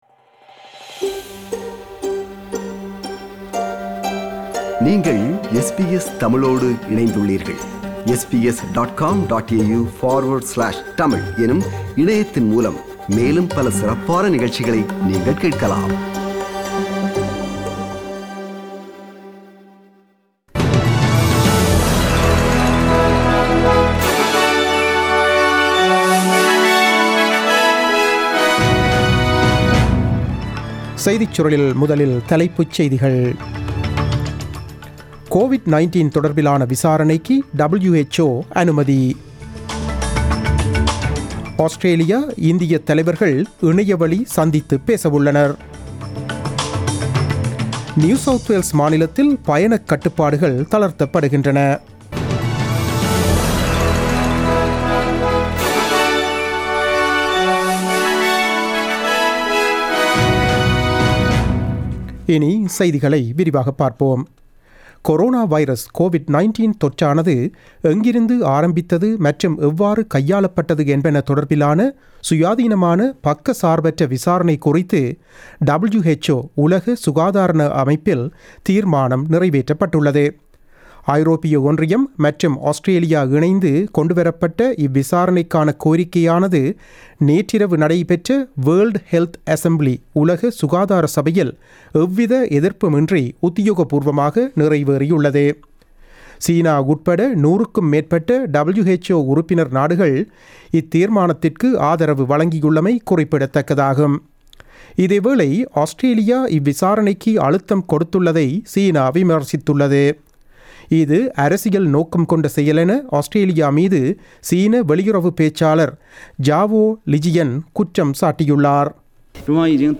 The news bulletin broadcasted on 20 May 2020 at 8pm.